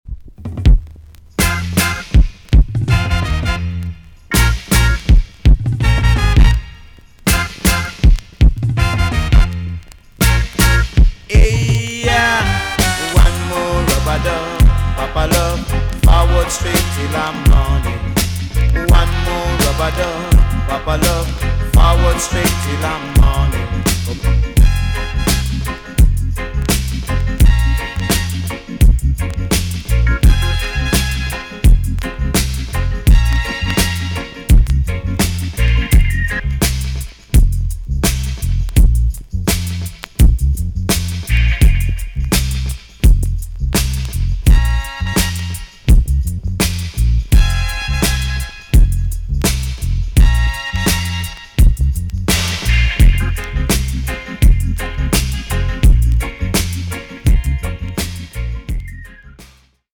TOP >DISCO45 >80'S 90'S DANCEHALL
B.SIDE Version
EX- 音はキレイです。